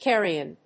音節car・ri・on 発音記号・読み方
/kˈæriən(米国英語), ˈkeri:ʌn(英国英語)/